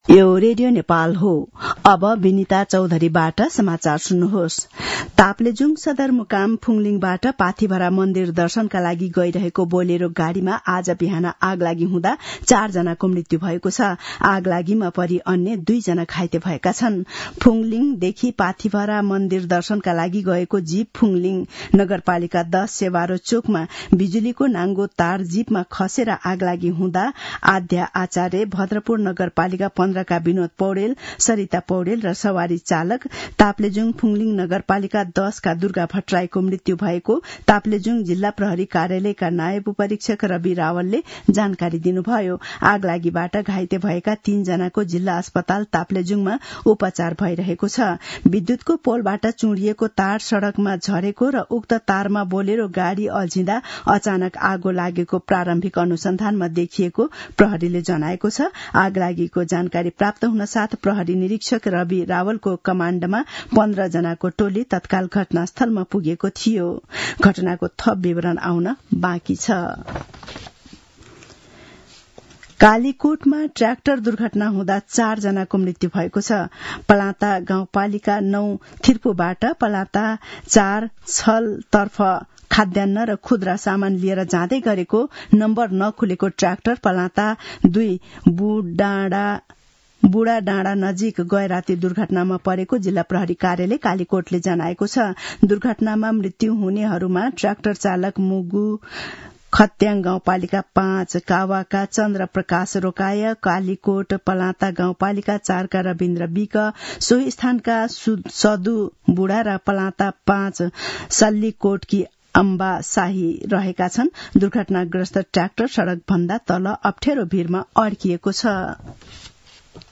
मध्यान्ह १२ बजेको नेपाली समाचार : ८ वैशाख , २०८२